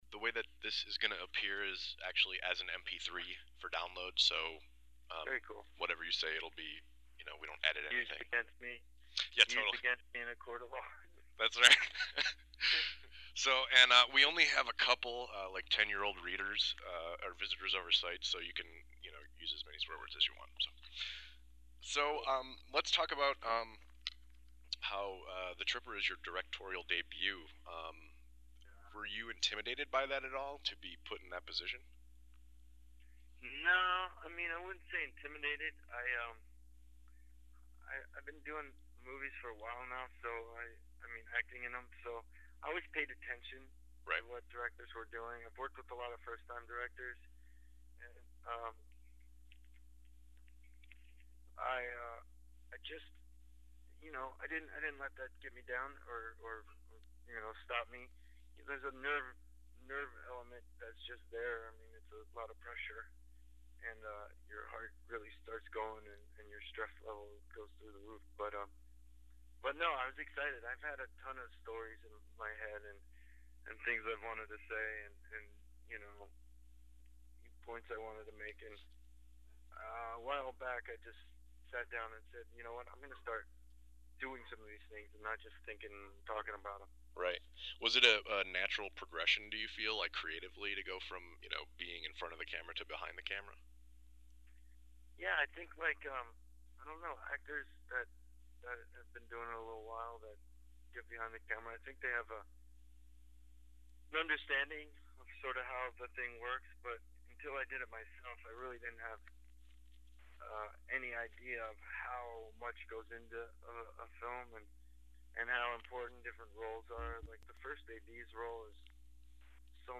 LAUNCH DAVID ARQUETTE INTERVIEW (MP3 FILE)(NOTE: File is large and may take awhile to fully load.)